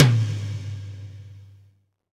TOM XTOMM0BR.wav